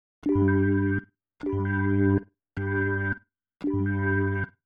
Эмулятор электрооргана Hammond - "Blue3" от новой фирмы GG Audio
Звук на высоте, реакция на игру вполне отменна для версии № 1.0.0...